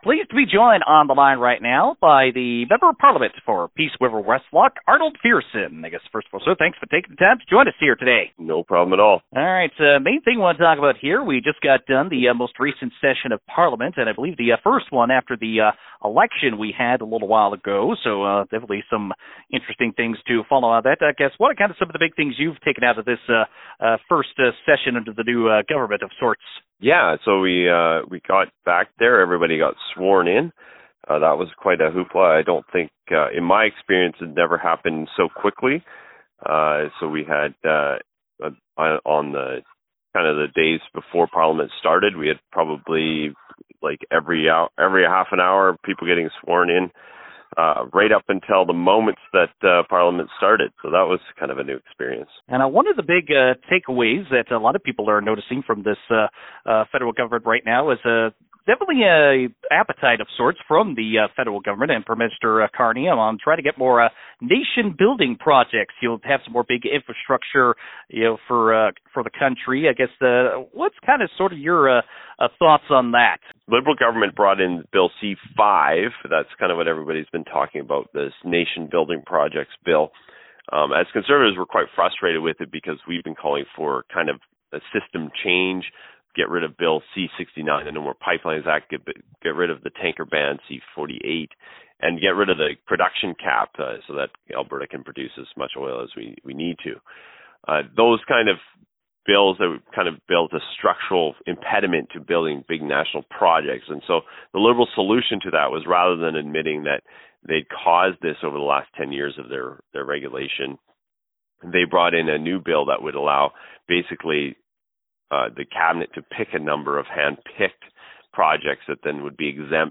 viersen-interview-july-2.wav